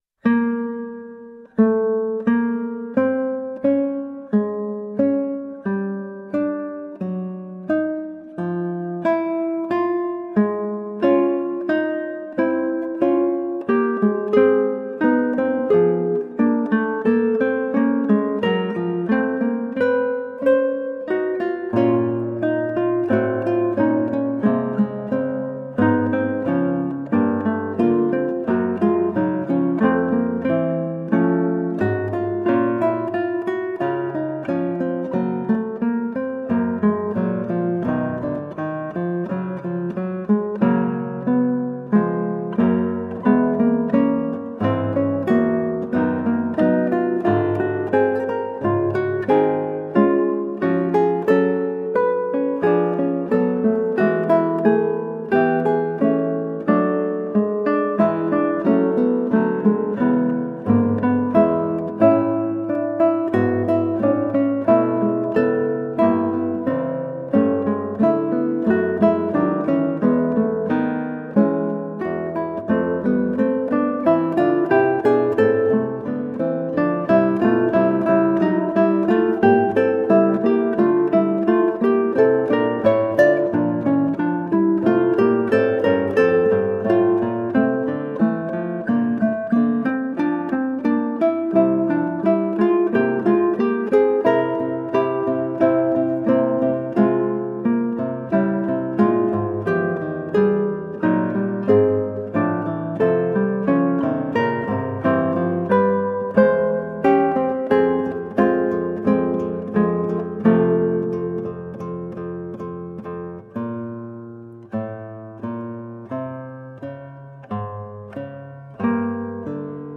Colorful classical guitar.
Instrumental
Classical Guitar